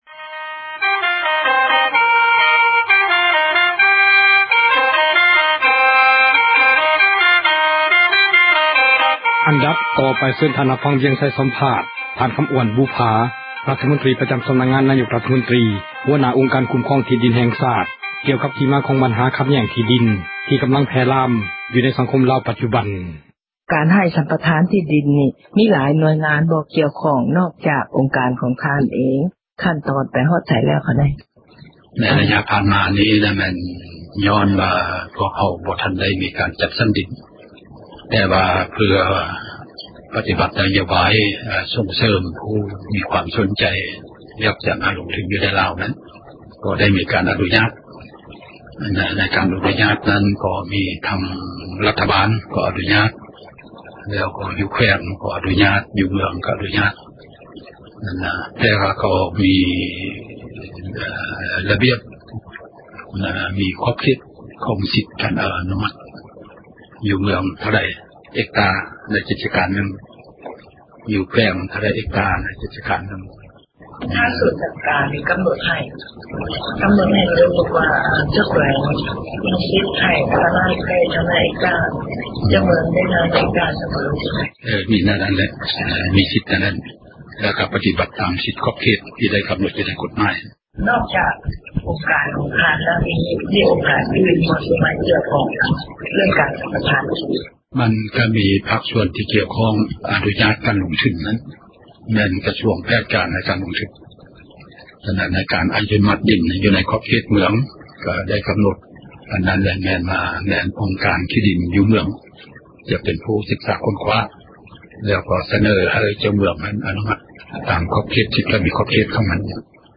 ສັມພາດທ່ານ ຄໍາອ້ວນ ບຸບຜາ ຣັຖມົນຕຣີ ປະຈໍາ ສໍານັກງານ ນາຍົກຣັຖມົນຕຣີ